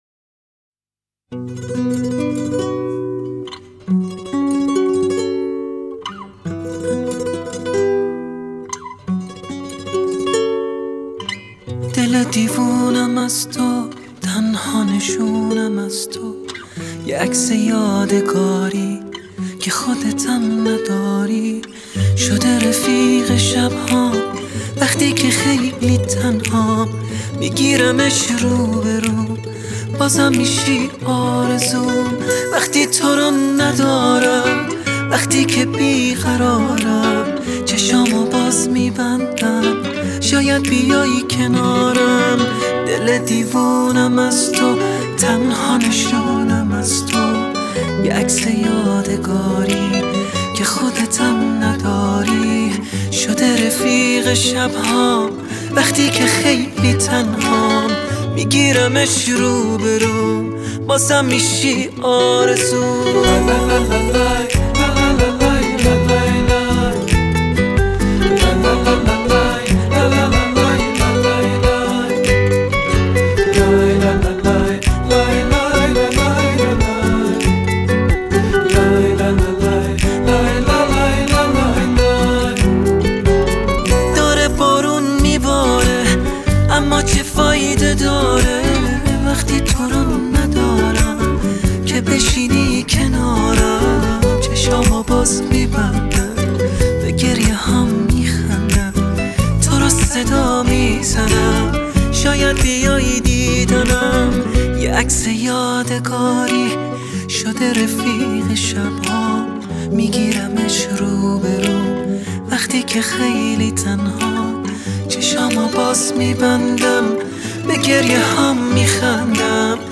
آهنگ تیتراژ